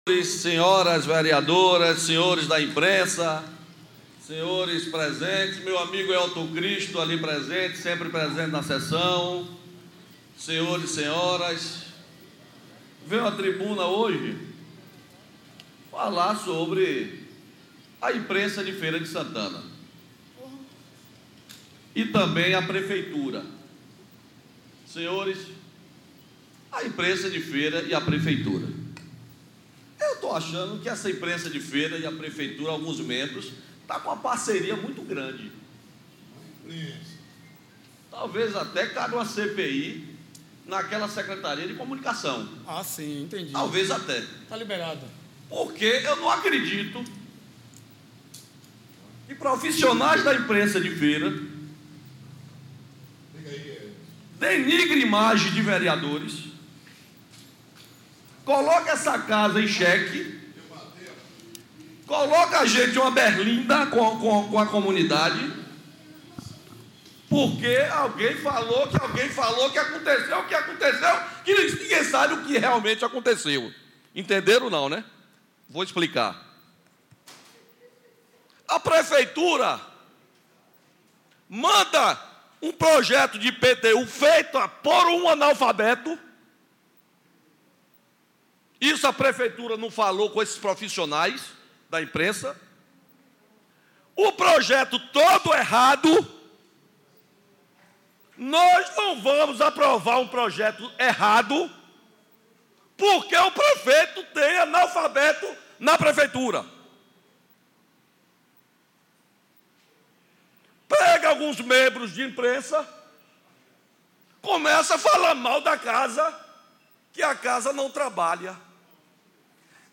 Em pronunciamento na Câmara Municipal na manhã desta terça-feira 30, o presidente da Casa, vereador Fernando Torres PSD, exigiu do prefeito que respeite vereadores.